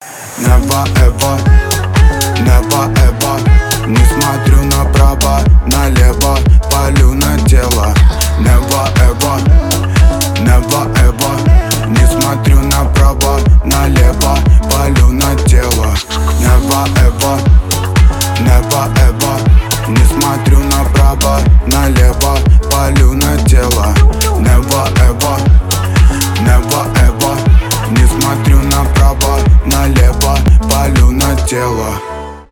танцевальные
хип-хоп